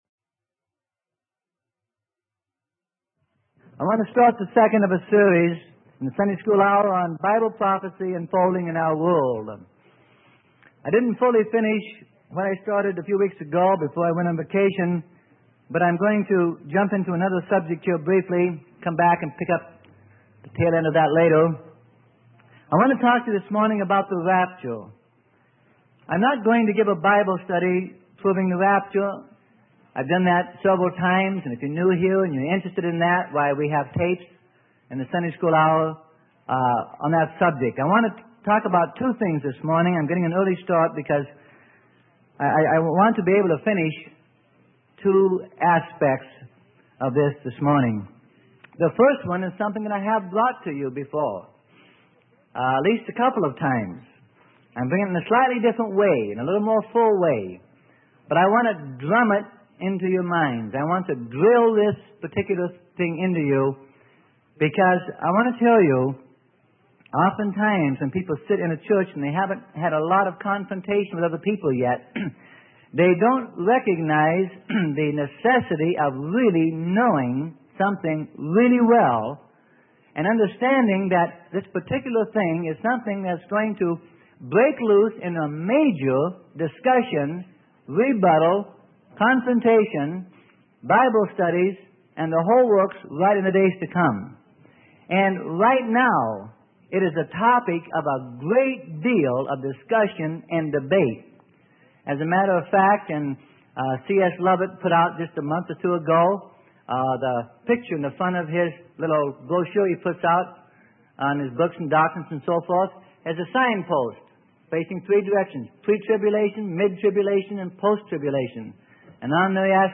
Sermon: Bible Prophecy Unfolding in Our World - Part 2; The word Rapture is in the Bible - Freely Given Online Library